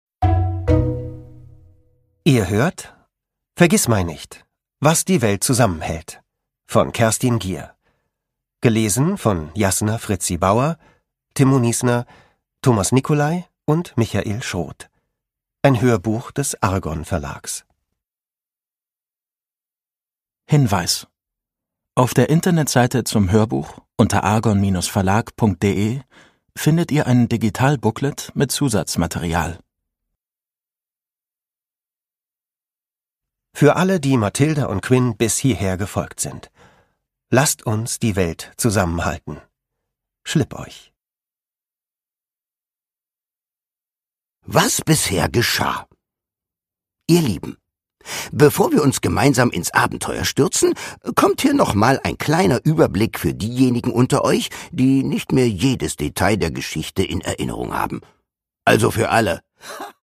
Produkttyp: Hörbuch-Download
Gelesen von: Timmo Niesner, Jasna Fritzi Bauer